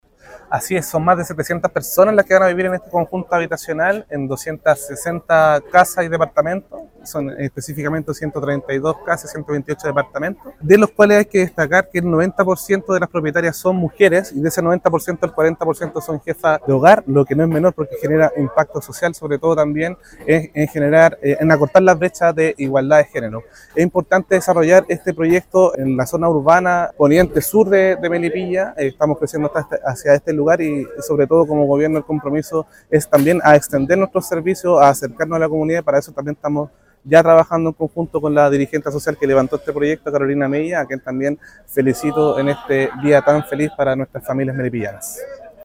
El sueño de la vivienda propia para 260 familias de Melipilla ya es realidad, tras la ceremonia de entrega del proyecto habitacional “Sol Poniente de Melipilla”.